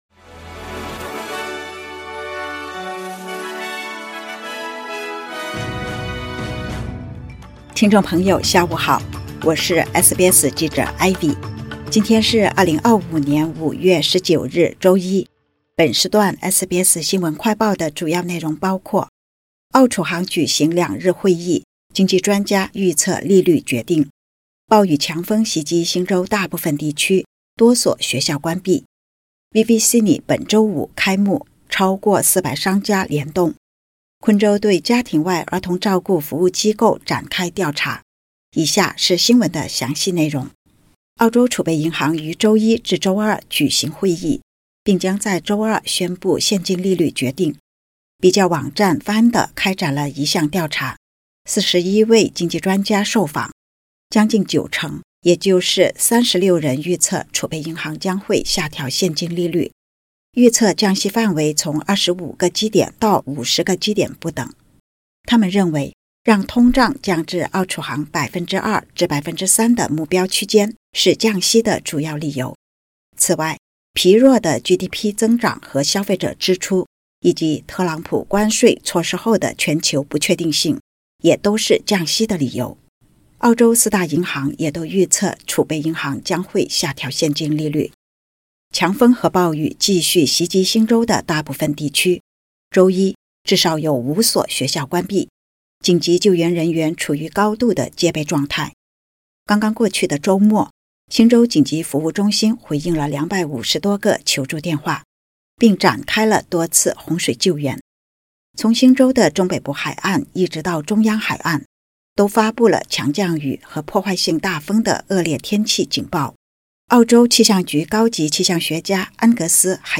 【SBS新闻快报】澳储行举行两日会议 经济专家预测利率走向